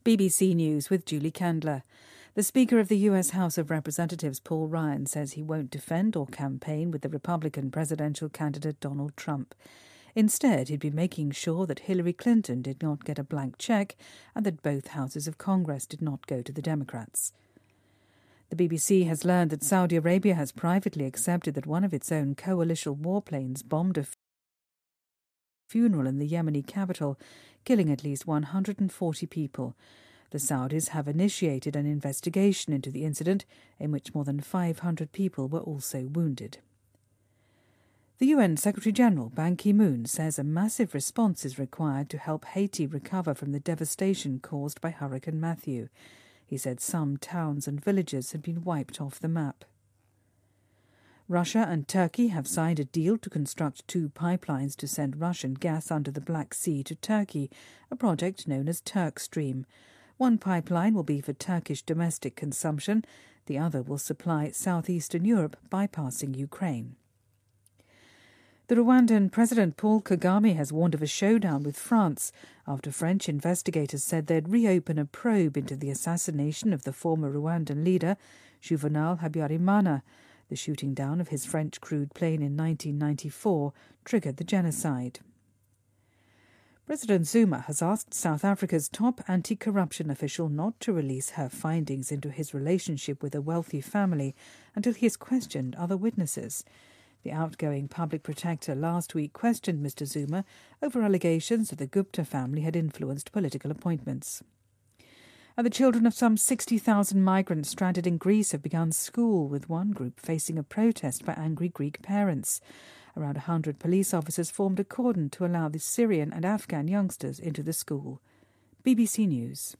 BBC news,众议院议长称不会维护特朗普